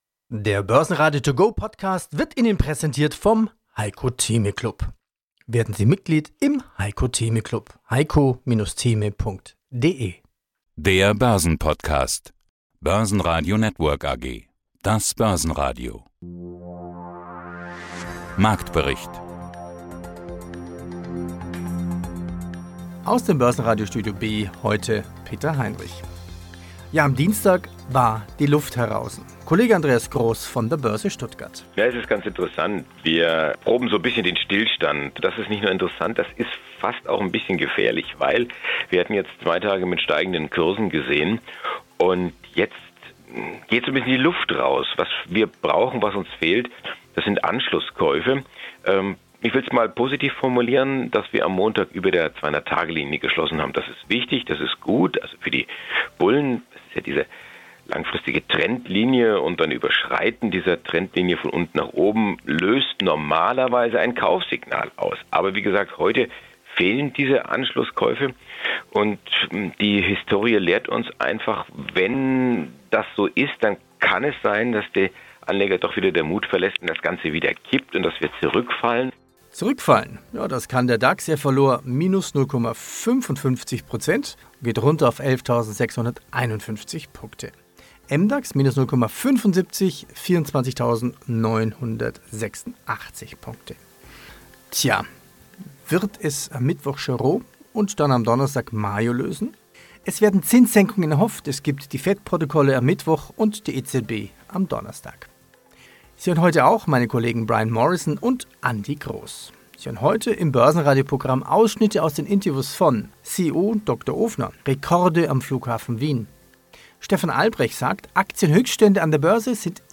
Die Börse zum hören: mit Vorstandsinterviews, Expertenmeinungen und Marktberichten.